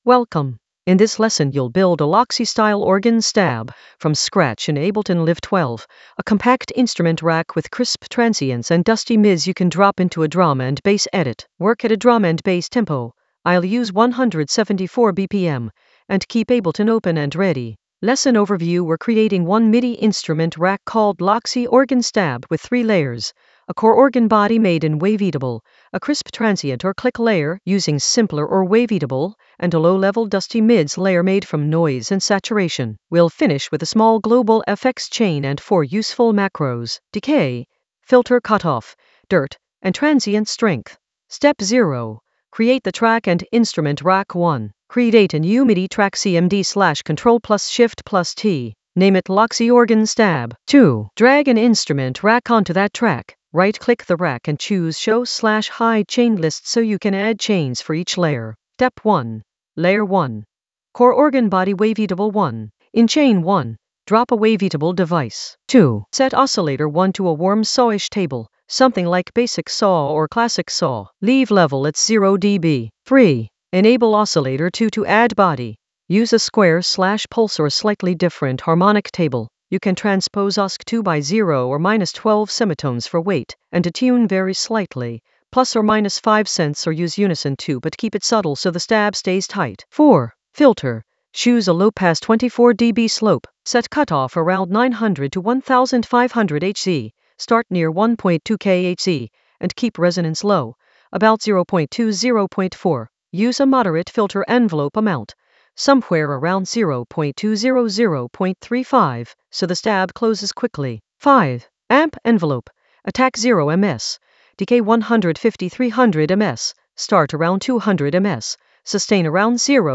An AI-generated beginner Ableton lesson focused on Loxy edit: layer a organ stab from scratch in Ableton Live 12 with crisp transients and dusty mids in the Edits area of drum and bass production.
Narrated lesson audio
The voice track includes the tutorial plus extra teacher commentary.